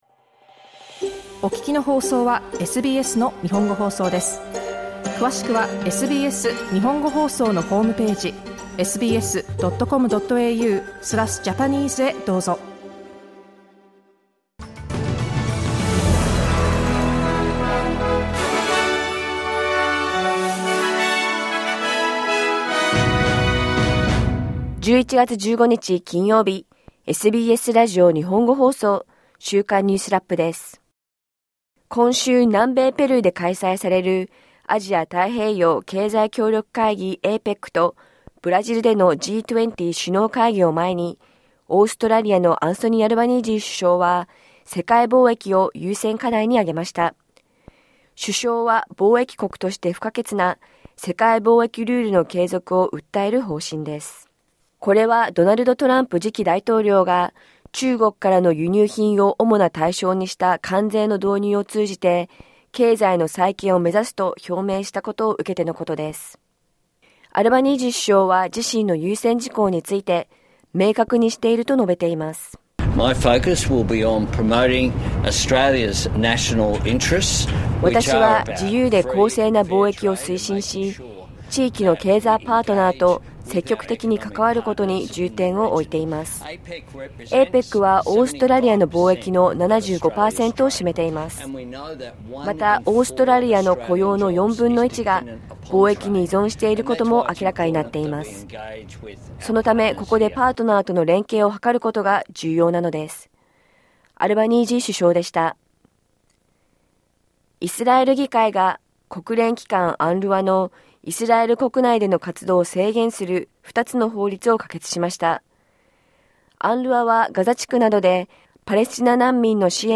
APECサミットを前に、オーストラリアは、優先課題として「世界貿易」をあげまた。1週間を振り返る週間ニュースラップです。